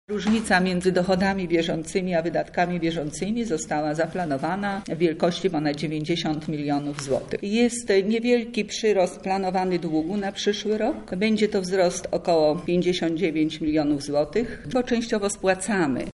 – mówi Irena Szumlak, skarbnik Lublina